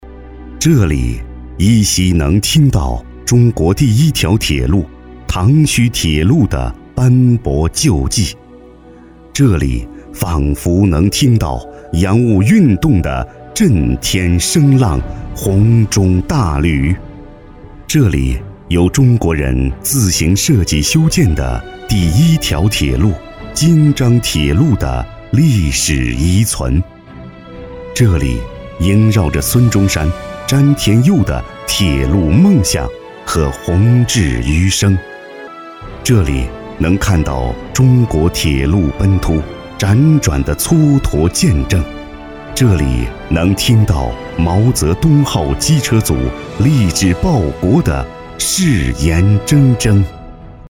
稳重磁性 企业专题,人物专题,医疗专题,学校专题,产品解说,警示教育,规划总结配音
大气磁性男音，擅长专题、讲述、记录片、旁白等题材。